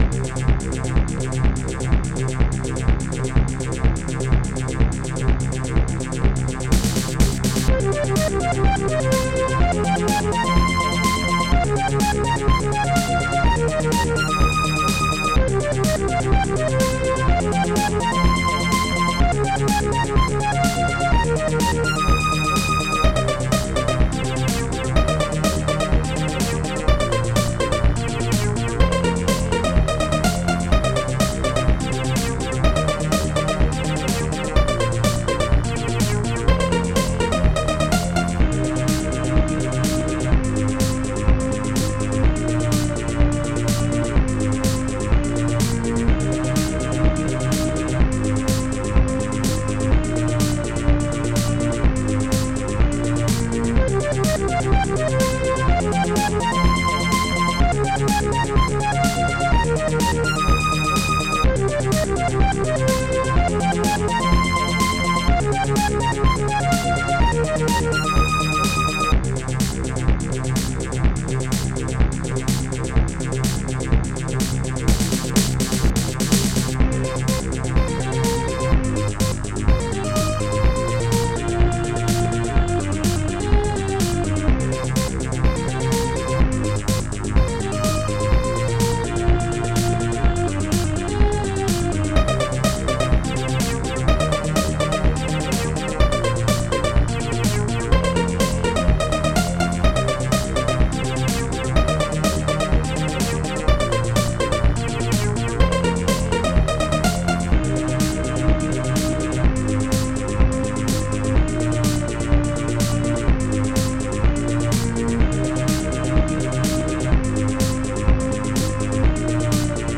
Protracker Module  |  1987-04-22  |  68KB  |  2 channels  |  44,100 sample rate  |  4 minutes, 28 seconds
Protracker and family
st-01:rubberbass
st-01:strings3
st-01:bassdrum1
st-01:popsnare2
st-01:hihat2